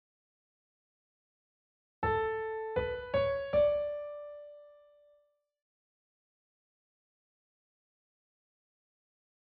ソルフェージュ 聴音: 1-ii-09